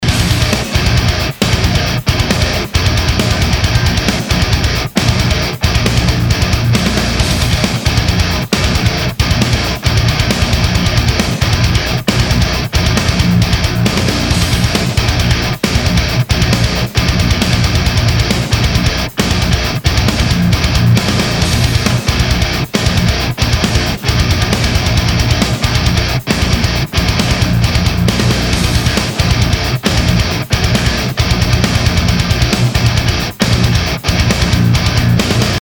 bis dahin hier schonmal ein kleines Betthupferl des TWO durch eine Marshall Box.....einzelnes SM57 mal wieder
ich habe die gleichen Settings benutzt, wie am Tag zuvor durch die Mesa Box, daher ist der Sound etwas fizzy, hätte ich anpassen sollen...der Grundsound kommt aber rüber denke ich